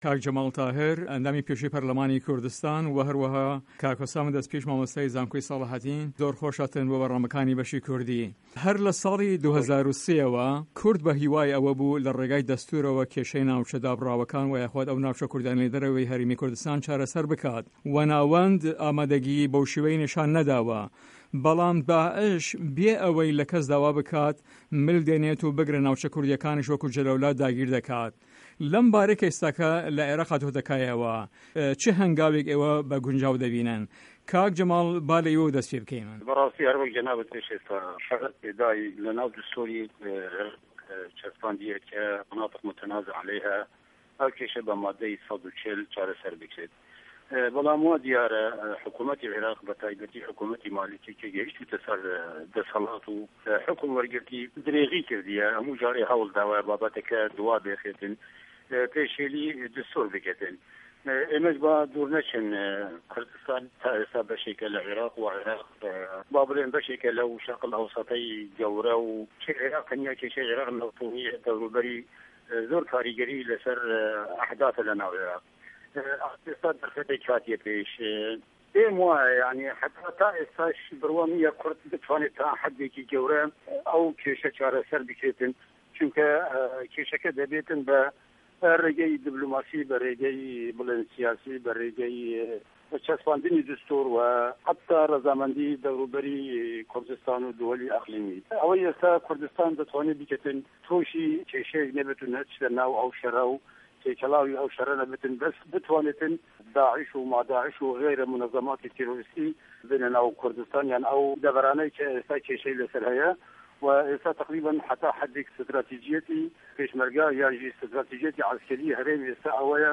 مێزگردی هه‌فته‌: به‌ندی 140 و ئه‌و ناوچانه‌ی تا دوێنی له‌ هه‌رێمی کوردستان دابڕابوون